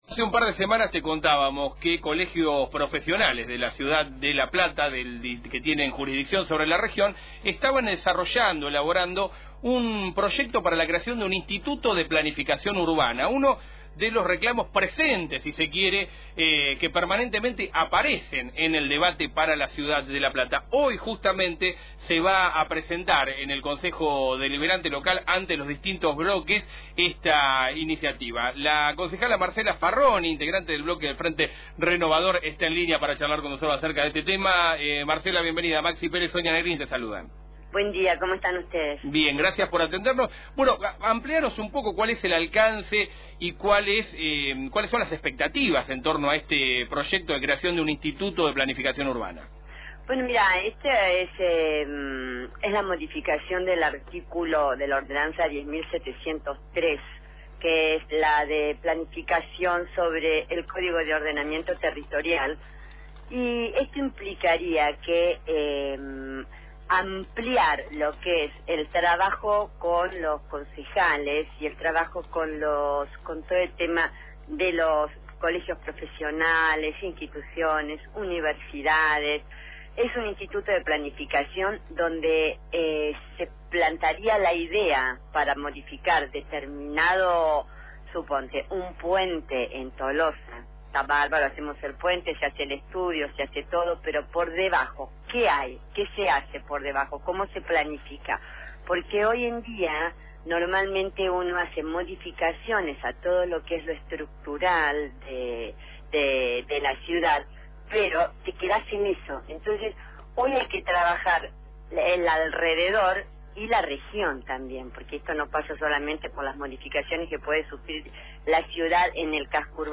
En diálogo con Radio Provincia, la edil explicó además que “este instituto estaría compuesto por un equipo consultor integrado por dos delegados de Colegios de profesionales, 4 representantes de la Universidad y 4 del municipio y tendrá 90 días para expedirse ante cualquier pedido”.